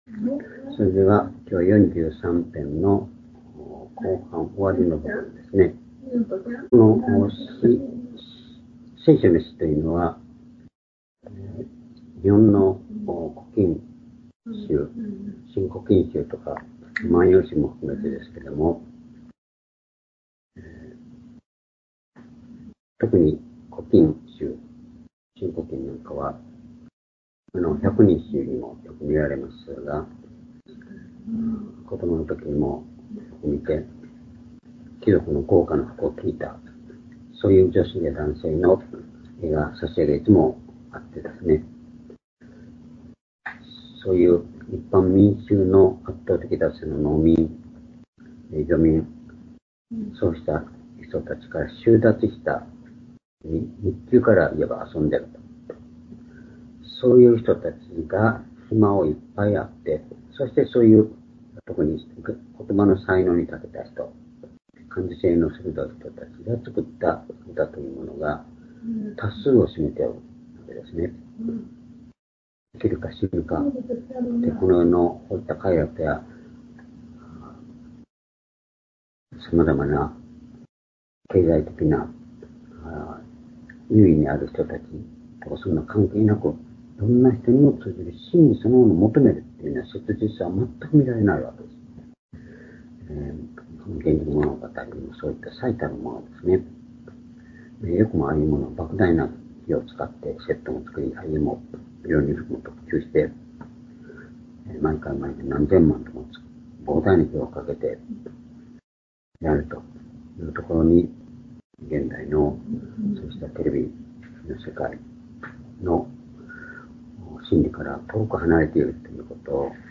（主日・夕拝）礼拝日時 2024年7月16日(夕拝) 聖書講話箇所 「なおも、主を待ち望み、讃美する」 詩編43編4～5節 ※視聴できない場合は をクリックしてください。